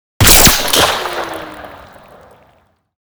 Railgun_Near_03.ogg